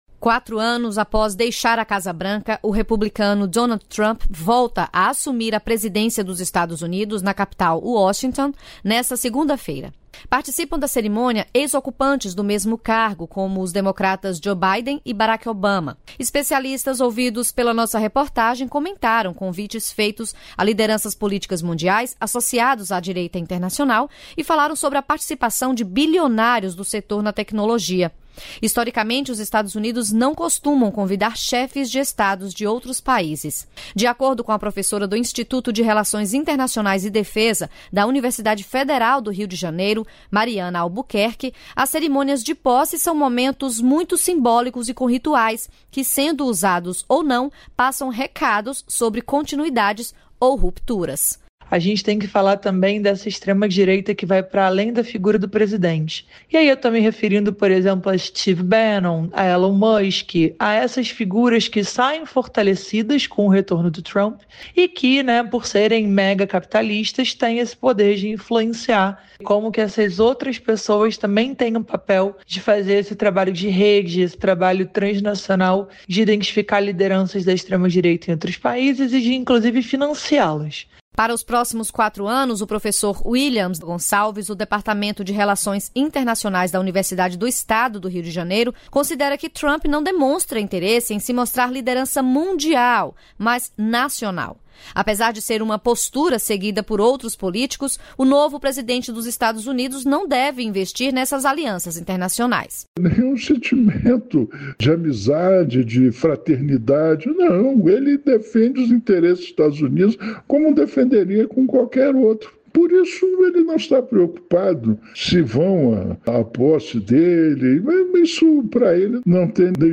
Especialistas ouvidos pela nossa reportagem comentaram convites feitos a lideranças políticas mundiais, associados à direita internacional, e falaram sobre a participação de bilionários do setor da tecnologia.